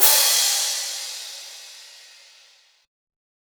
Crashes & Cymbals
TM-88 Crash #06.wav